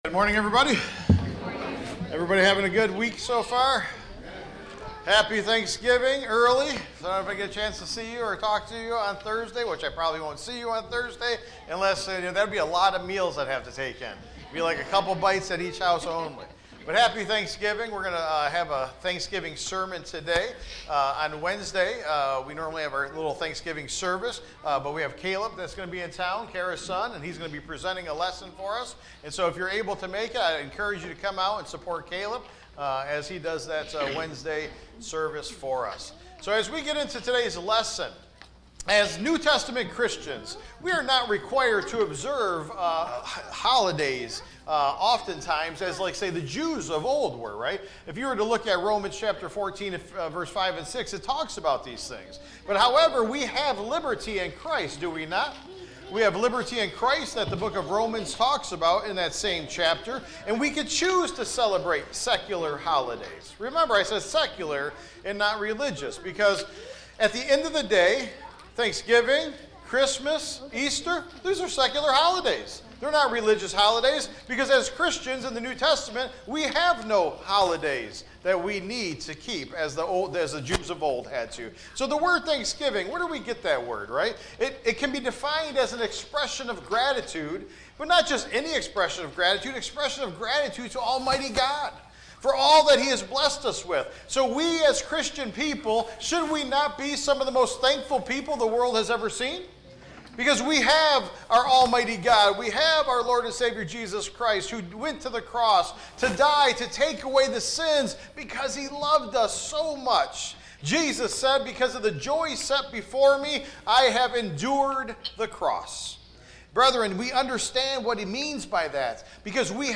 Thanksgiving Lincoln Park Church Of Christ Sermons podcast